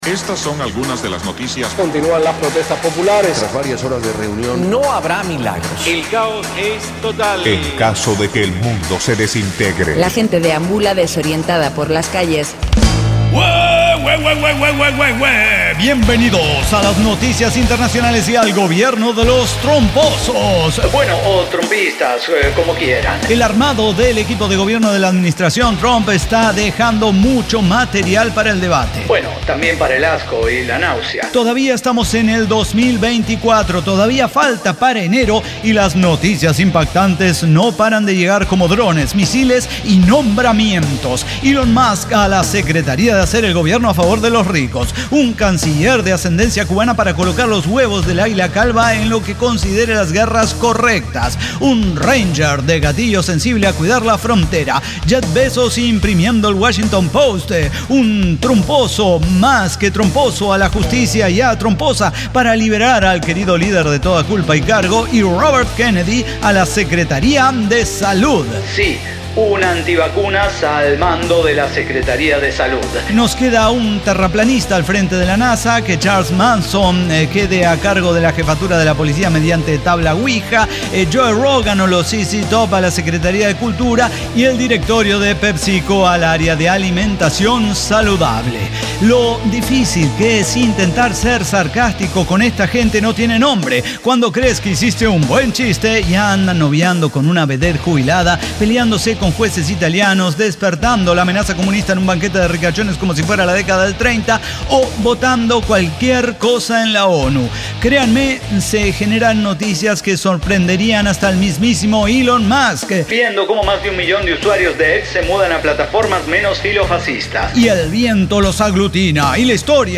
ECDQEMSD podcast El Cyber Talk Show – episodio 5916 Los Trumposos